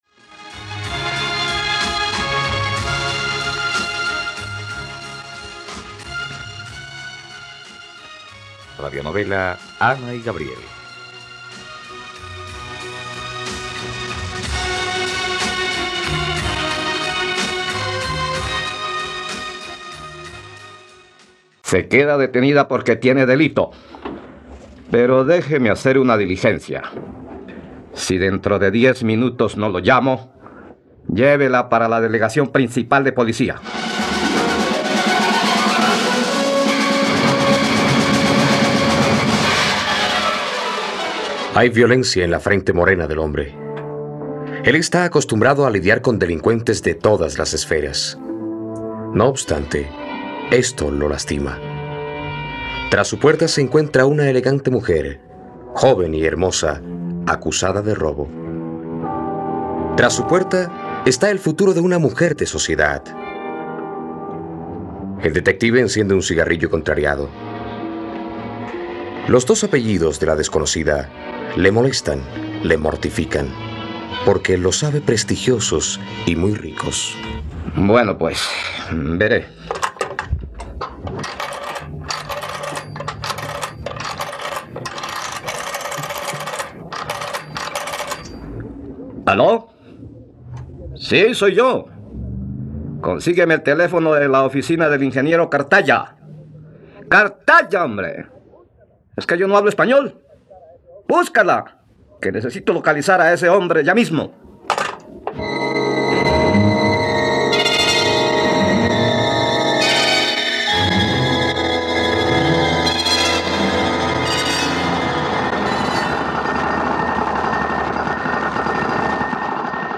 Ana y Gabriel - Radionovela, capítulo 15 | RTVCPlay